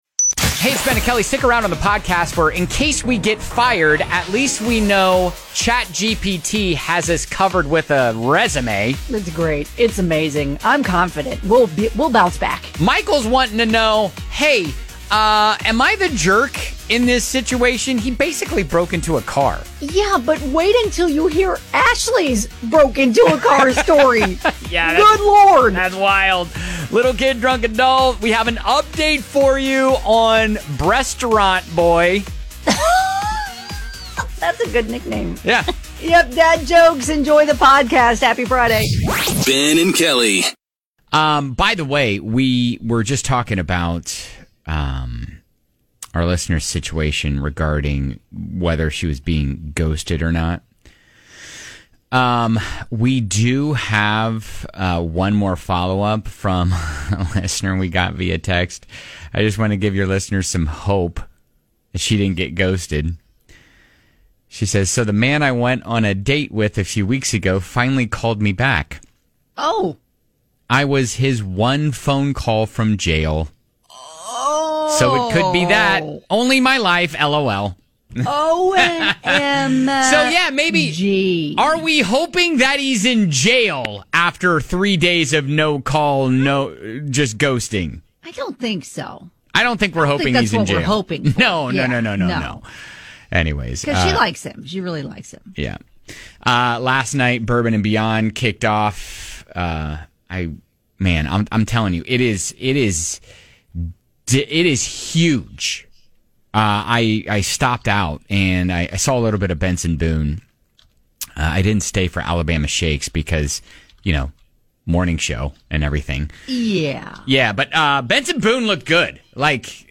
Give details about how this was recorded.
The whole show (minus music and commercials!)